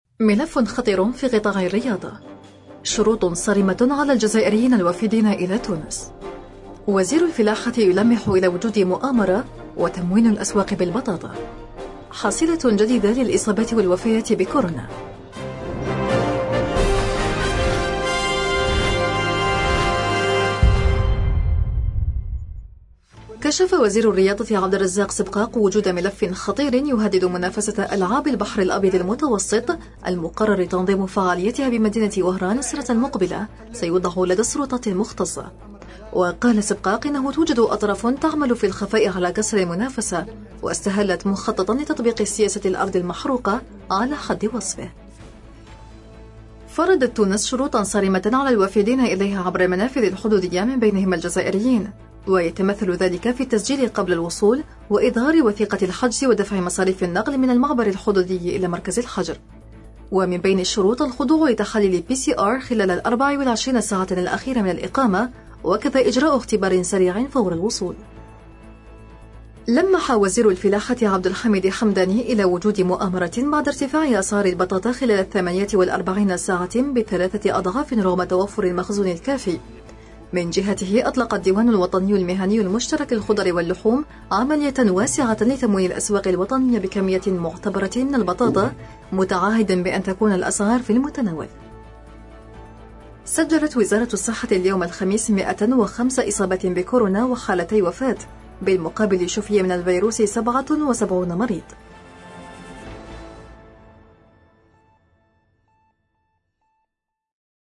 النشرة اليومية: ملف “خطير” في قطاع الرياضة – أوراس